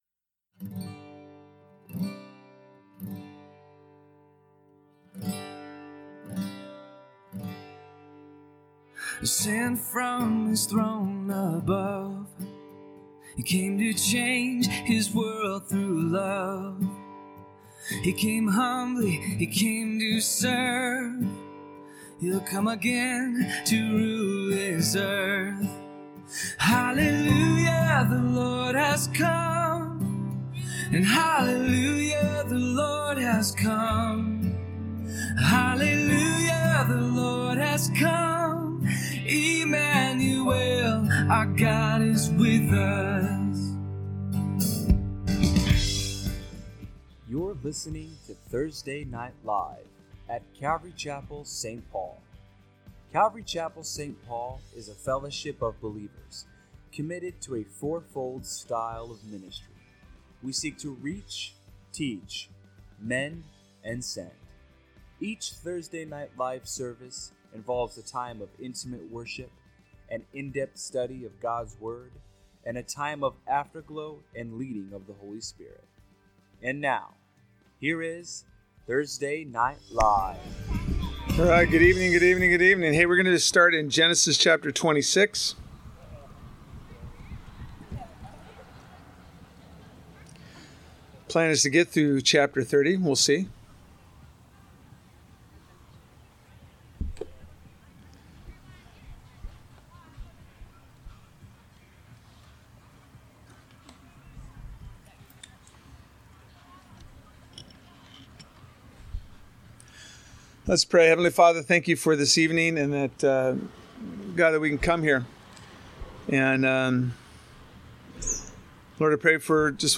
A message from the series "Thursday Evening."